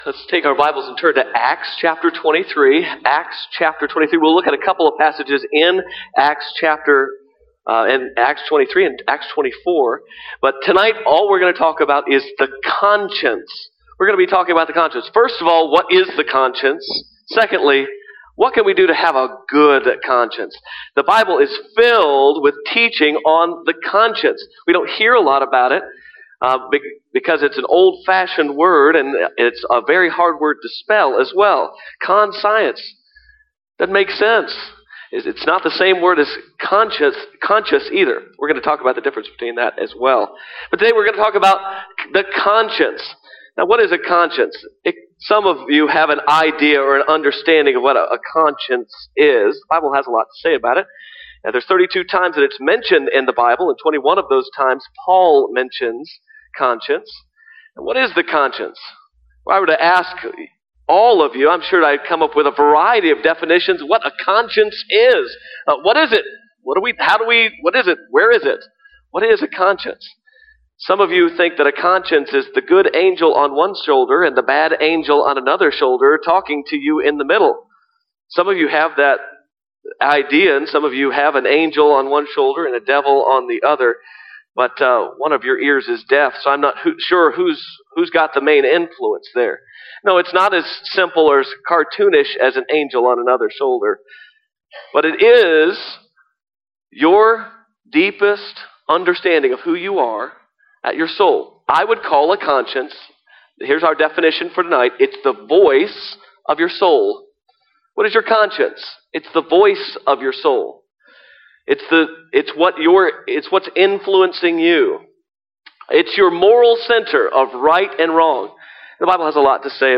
Media of Worth Baptist Church of Fort Worth, Texas
Sermons